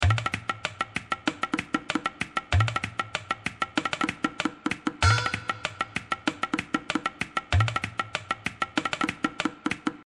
PW Tabla Loop 3
Tag: 96 bpm Ethnic Loops Tabla Loops 1.69 MB wav Key : Unknown